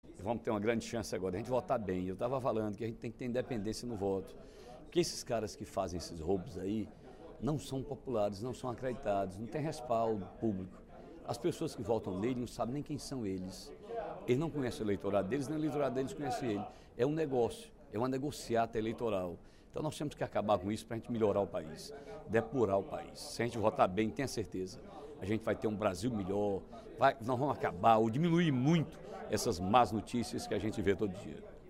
O deputado Ferreira Aragão (PDT) manifestou incômodo, durante o primeiro expediente da sessão plenária desta terça-feira (30/05), com o excesso de notícias negativas sobre a política brasileira.